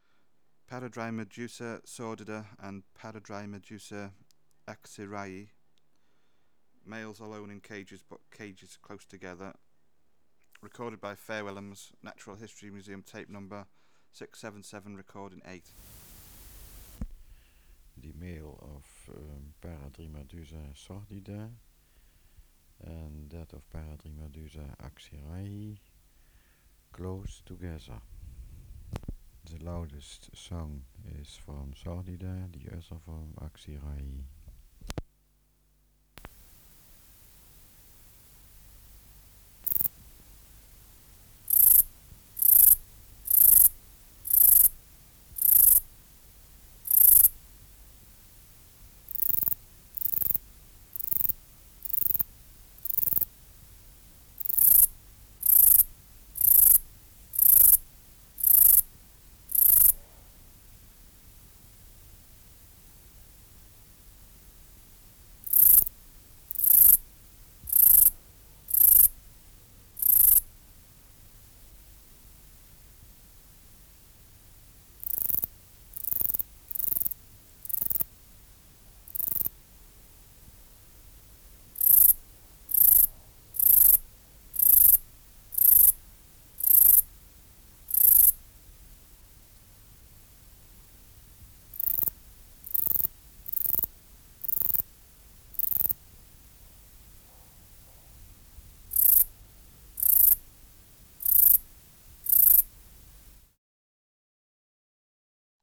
568:33 Paradrymadusa sordida & Paradrymadusa aksirayi (677r8) | BioAcoustica
Air Movement: Nil Light: Sunny Substrate/Cage: In cages
Males alone in cages but cages close together
Microphone & Power Supply: AKG D202E (LF circuit off) Distance from Subject (cm): 10